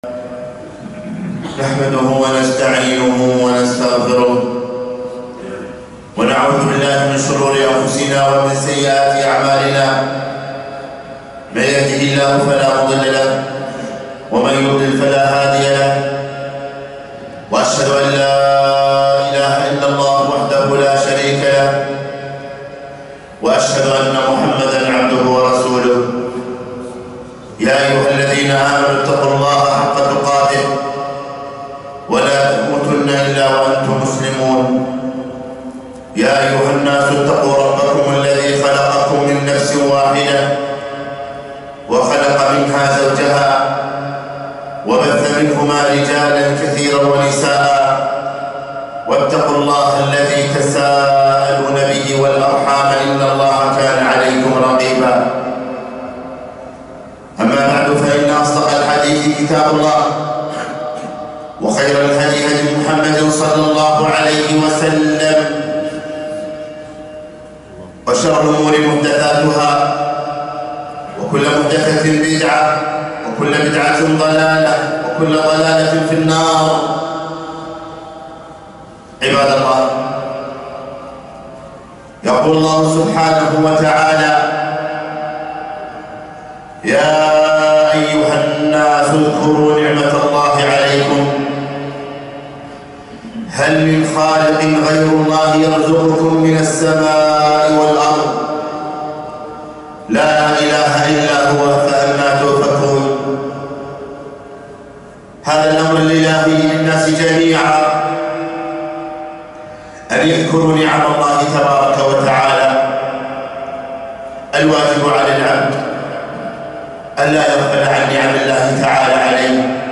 خطبة - اذكروا نعمة الله عليكم